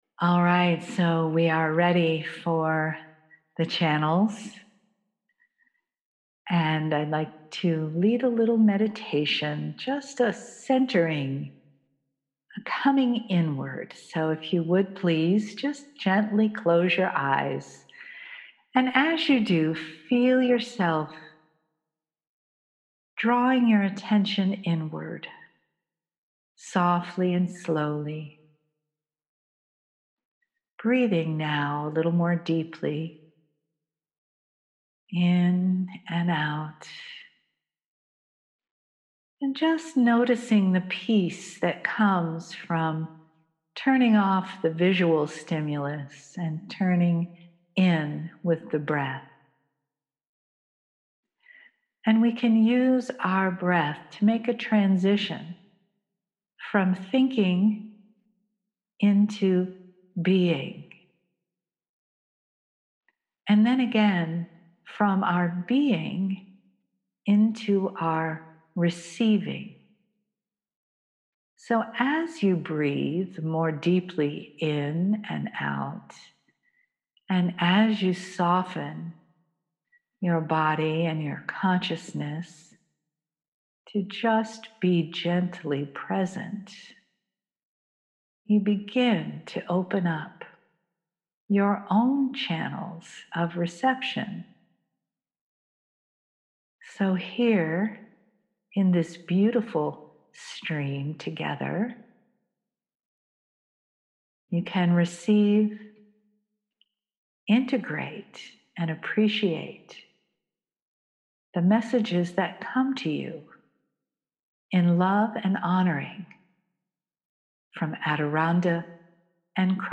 Kryon Streaming - Seattle - May 9-10, 2020
MULTI-CHANNELLING